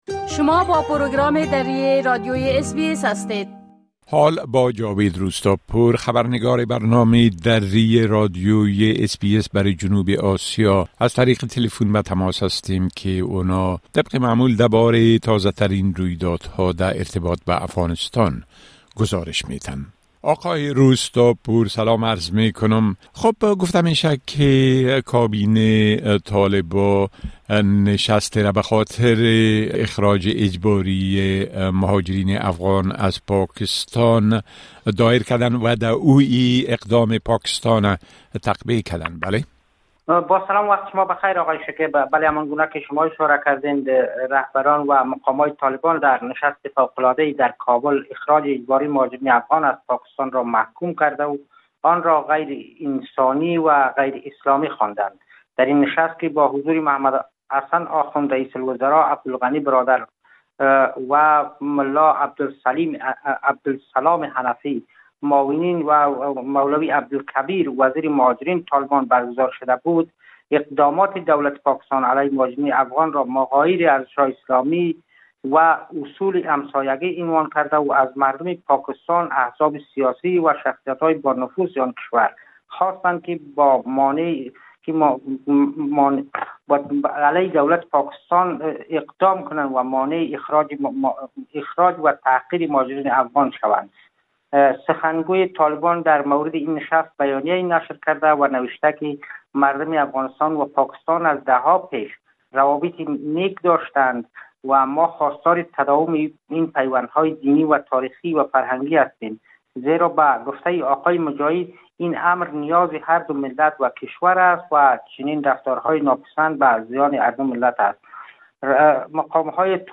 خبرنگار ما برای جنوب آسیا: حكومت طالبان اقدام پاكستان براى اخراج مهاجرين افغان را محكوم كرده است
گزارش كامل خبرنگار ما، به شمول اوضاع امنيتى و تحولات مهم ديگر در افغانستان را در اين‌جا شنيده مى توانيد.